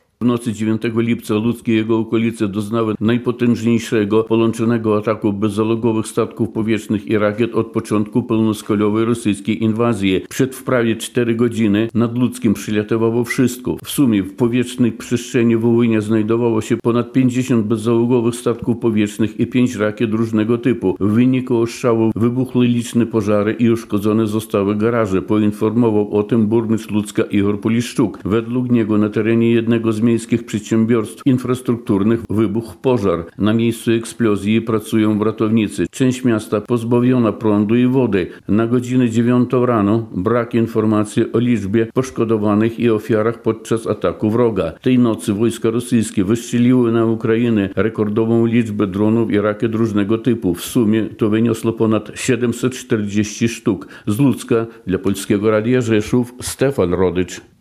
Z Łucka dla Polskiego Radia Rzeszów